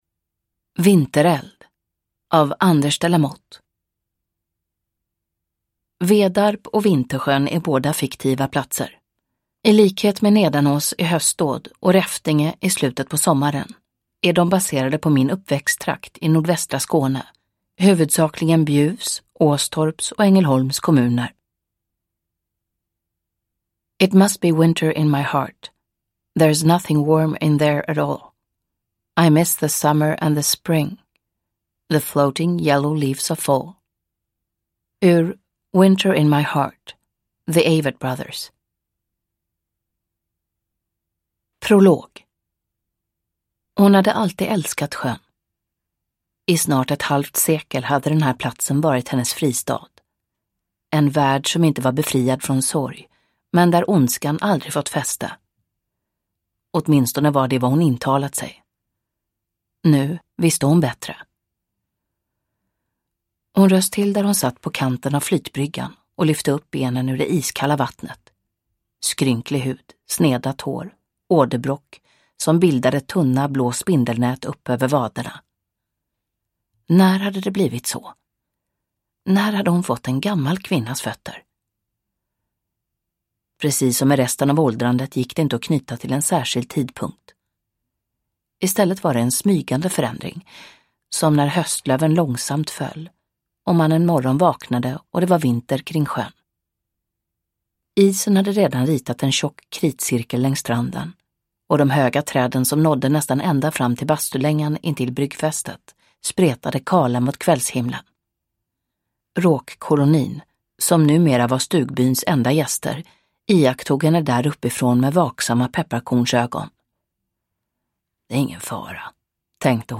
Vintereld – Ljudbok
Uppläsare: Mirja Turestedt